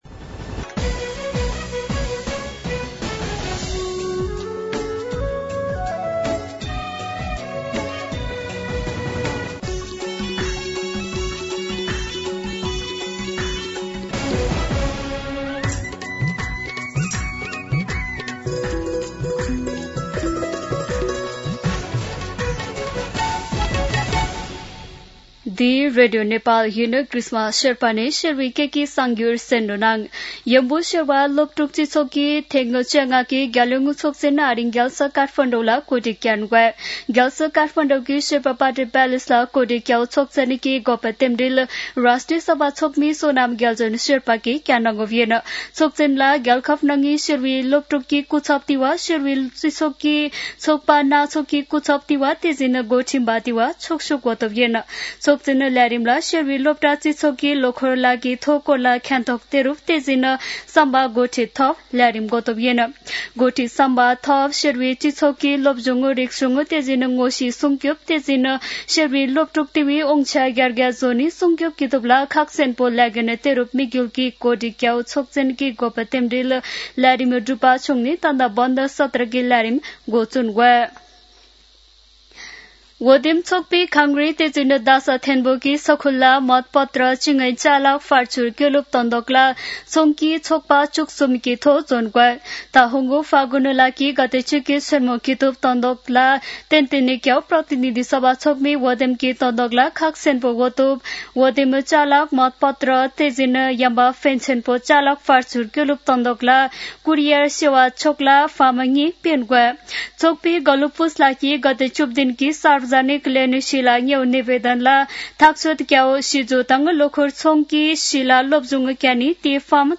शेर्पा भाषाको समाचार : ३ माघ , २०८२
Sherpa-News-10-3.mp3